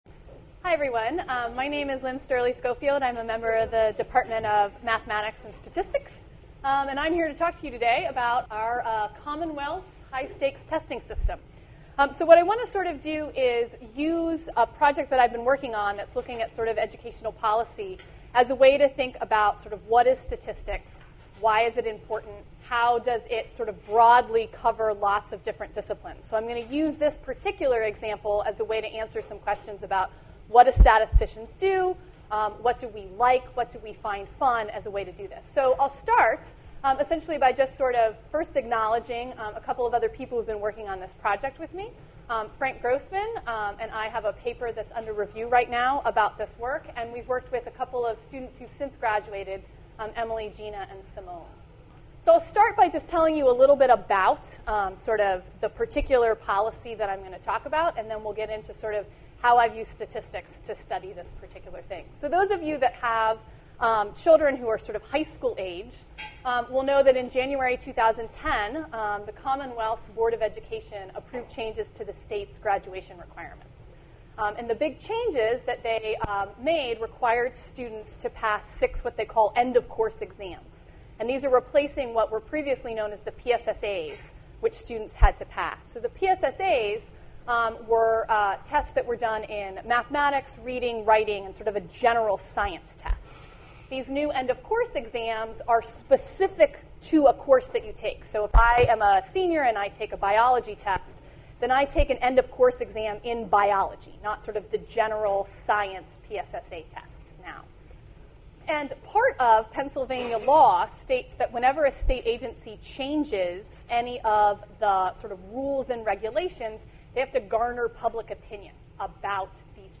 gives the second lecture in the Science Cafe series.